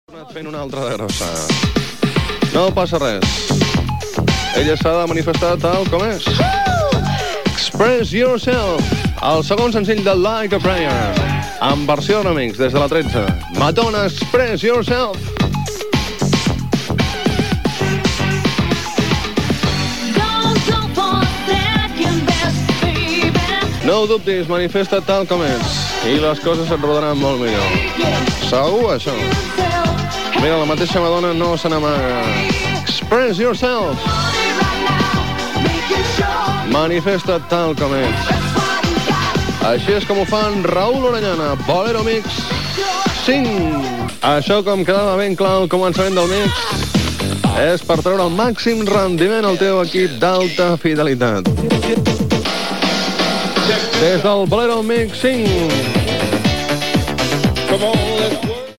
Presentació de temes musicals